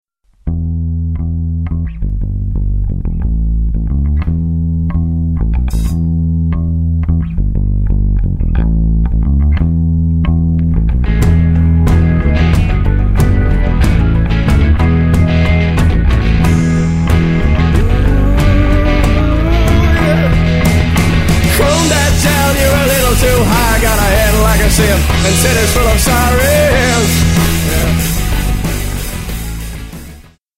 5 piece Indie Rock Band
Alternative,Rock